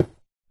Sound / Minecraft / dig / stone3.ogg
stone3.ogg